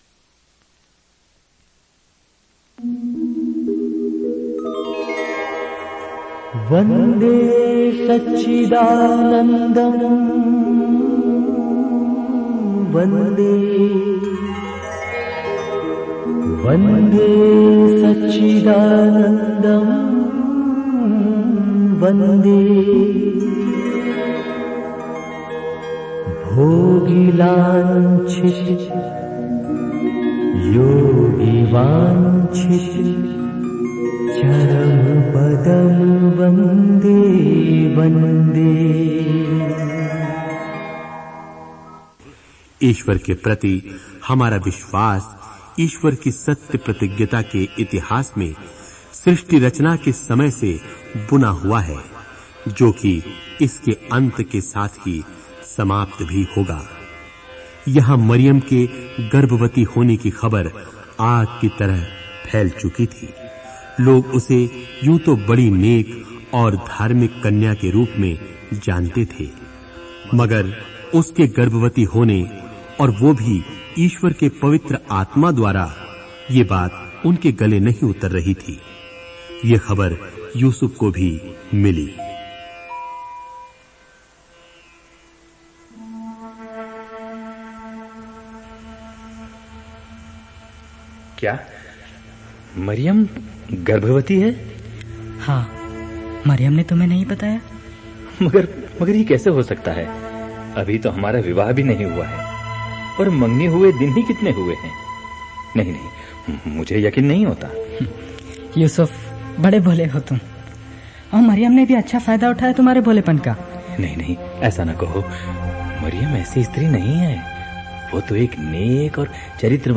Directory Listing of mp3files/Hindi/Bible Dramas/Dramas/ (Hindi Archive)